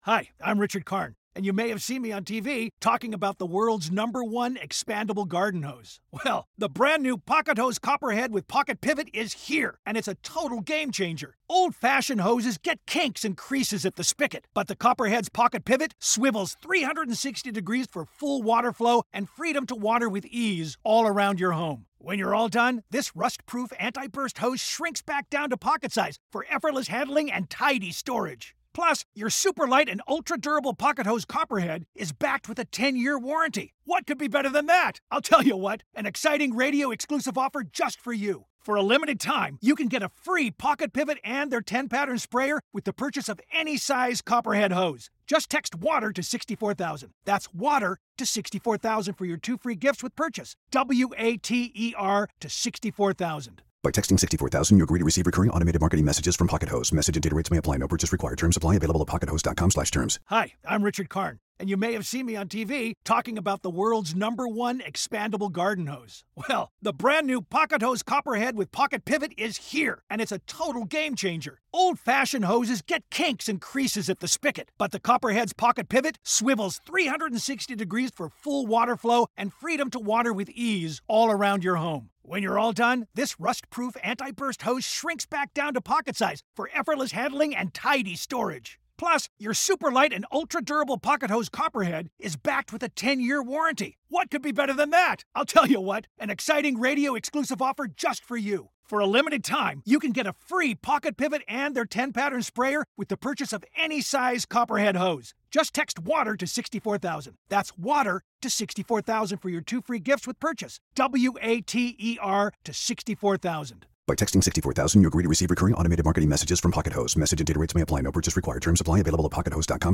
Jill Wine-Banks interviews Elie Mystal, political commentator and author, about his journey from law to journalism. They discuss historical laws’ impact on modern society, personal experiences with discrimination, and legal action against injustices.